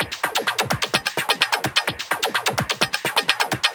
VEH1 Fx Loops 128 BPM
VEH1 FX Loop - 13.wav